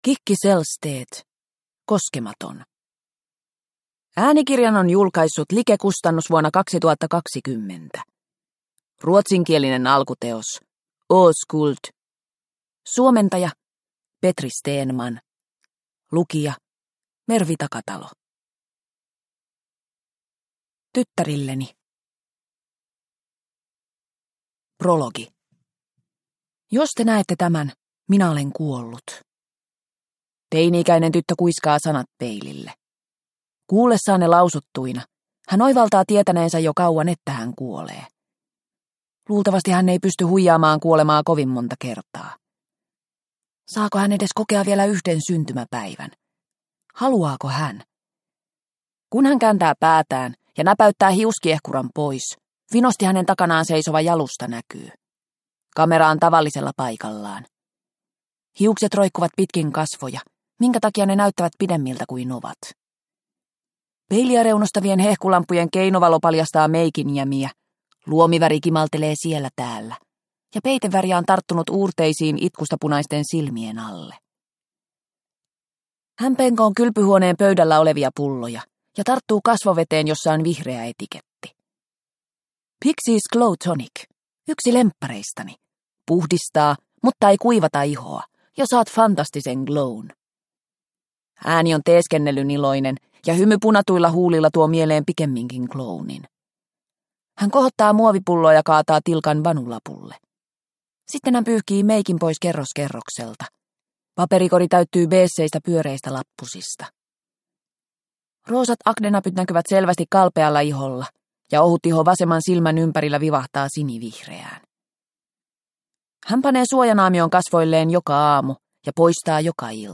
Koskematon – Ljudbok – Laddas ner